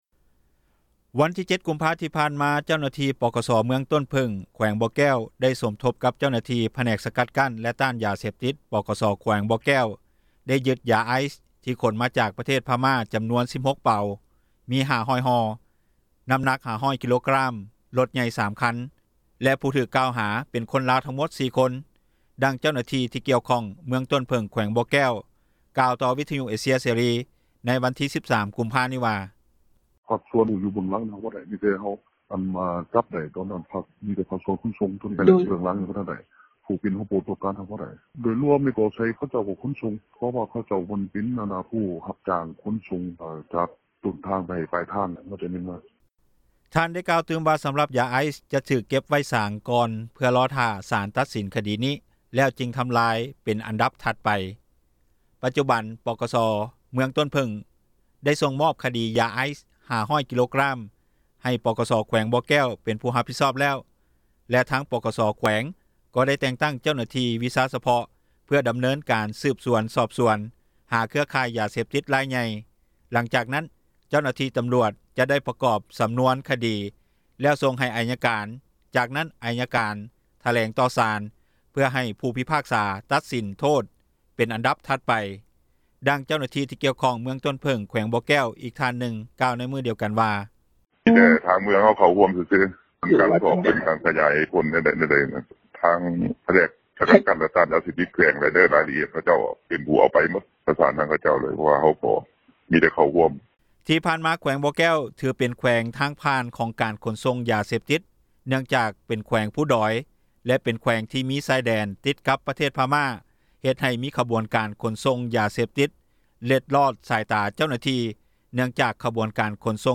ດັ່ງເຈົ້າຫນ້າທີ່ ທີ່ກ່ຽວຂ້ອງ ເມືອງຕົ້ນເຜິ້ງ ແຂວງບໍ່ແກ້ວ ກ່າວຕໍ່ວິທຍຸ ເອເຊັຽ ເສຣີ ໃນວັນທີ 13 ກຸມພາ ນີ້ວ່າ:
ດັ່ງເຈົ້າໜ້າທີ່ ທີ່ກ່ຽວຂ້ອງ ແຂວງບໍ່ແກ້ວ ກ່າວໃນມື້ດຽວກັນວ່າ:
ດັ່ງຊາວບ້ານ ຢູ່ເມືອງຫ້ວຍຊາຍ ແຂວງບໍ່ແກ້ວ ກ່າວໃນມື້ດຽວກັນນີ້ວ່າ: